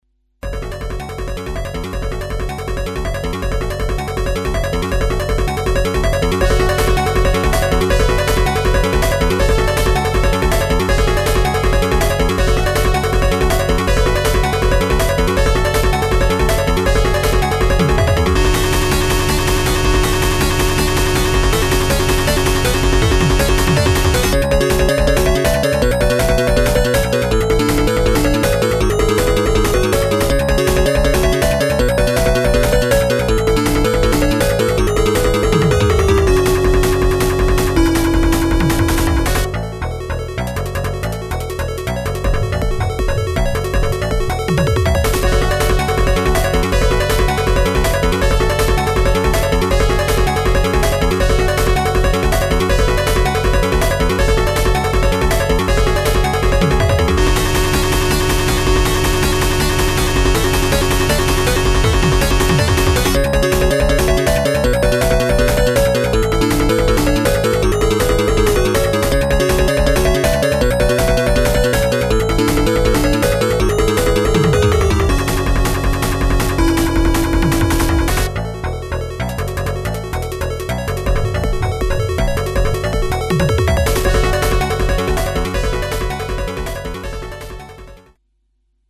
「ＦＭ音源」
というのは嘘で、ＰＣ−９８２１からサルベージしてきたＦＭ音源＋ＳＳＧ音源の曲。